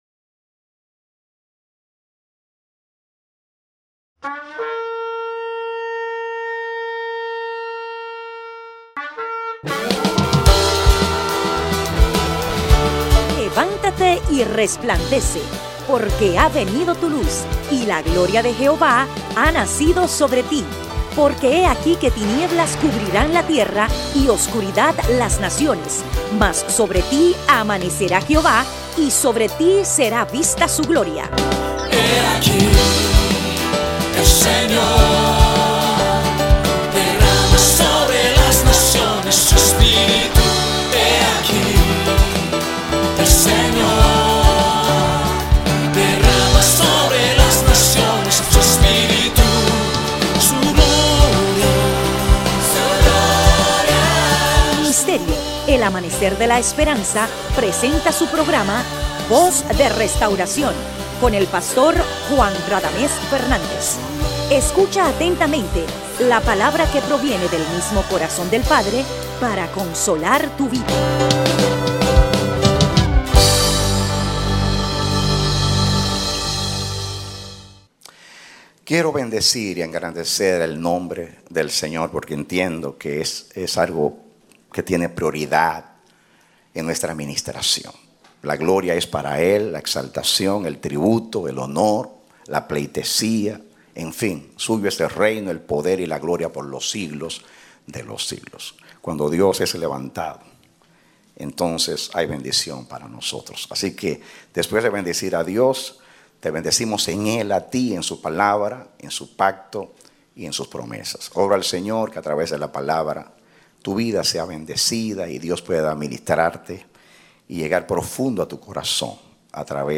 Predicado Domingo 10 de Julio, 2016